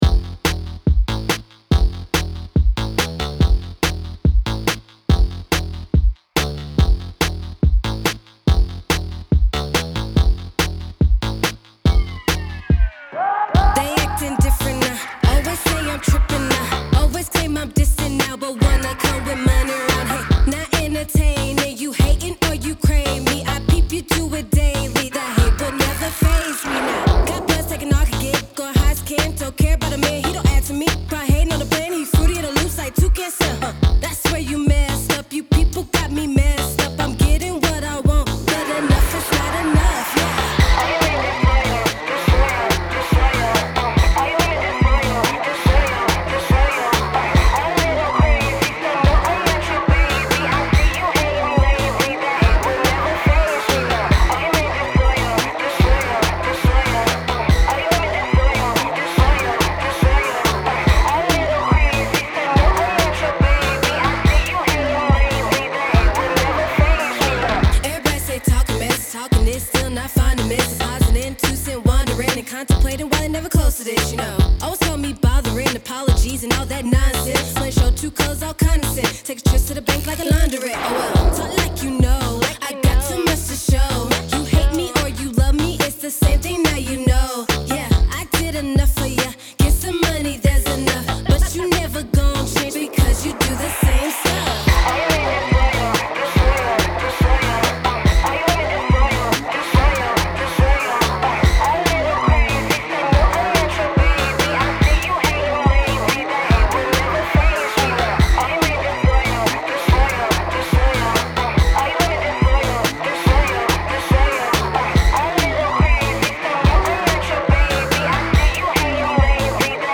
Hiphop
Description : Pop/Alternative Rock Artist